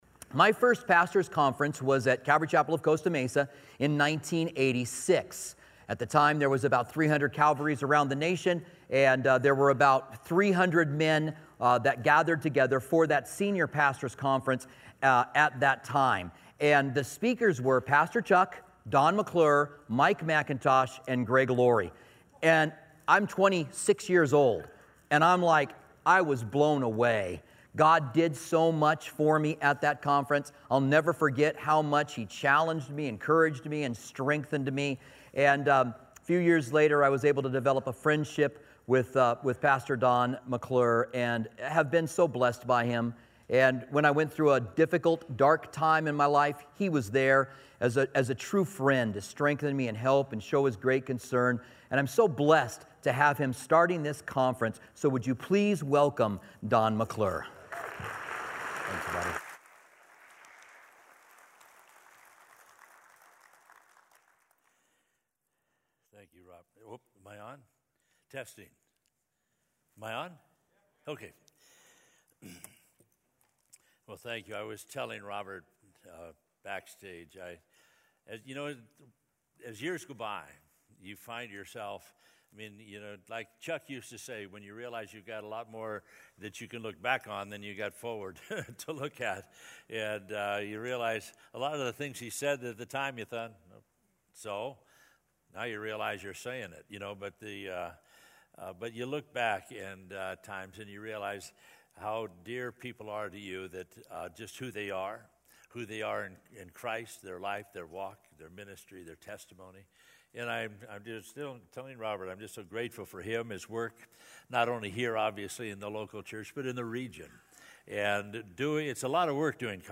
at the 2018 Southwest Pastors and Leaders Conference